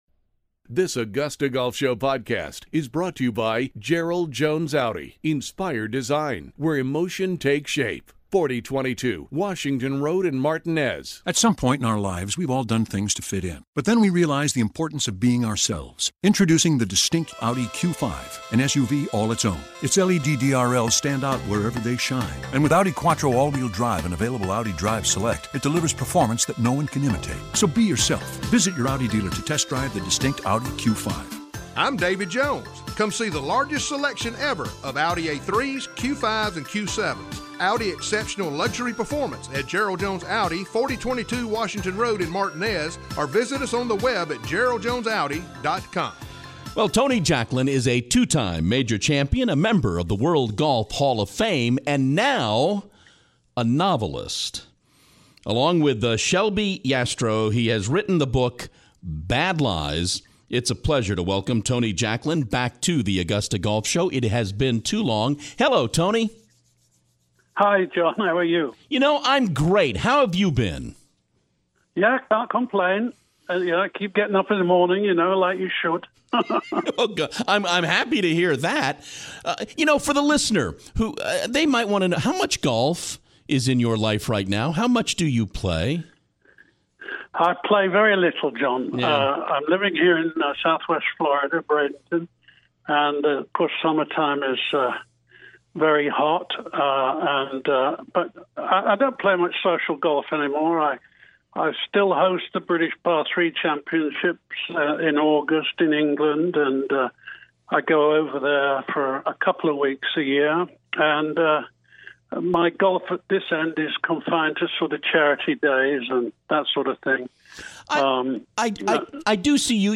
Two time major champion, World Golf Hall of Fame member, Tony Jacklin is back on the show, this time to talk about the novel he has co-authored, the book is called "Bad Lies"